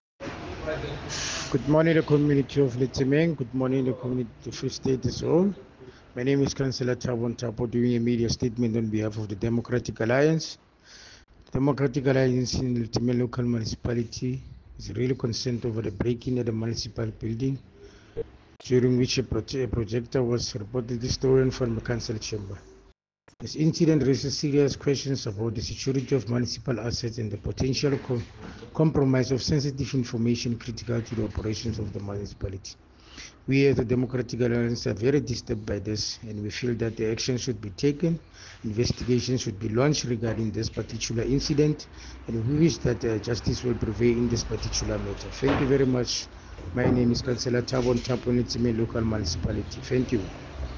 English soundbite by Cllr Thabo Nthapo and